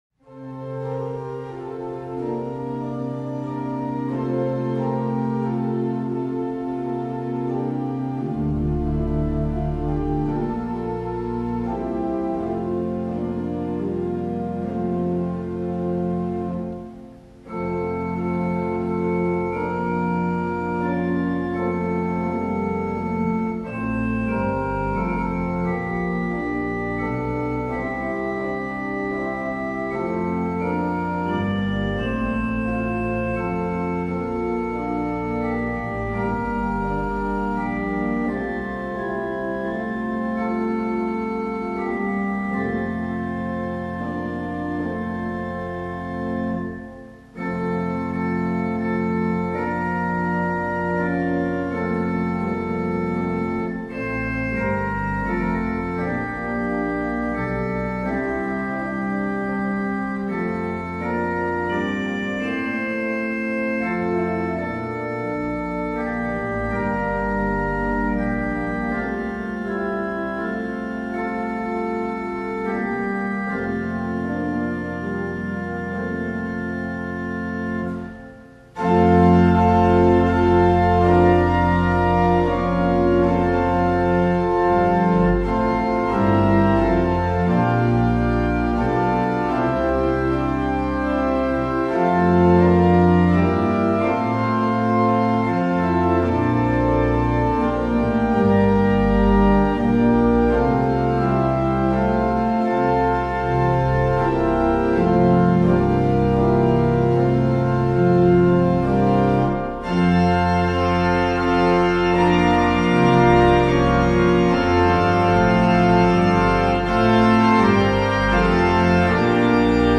Tune: Trentham Traditional Hymn: Breathe on Me, Breath of God
Hymn-Breathe-on-Me-Breath-of-God.mp3